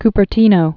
(kpər-tēnō, ky-)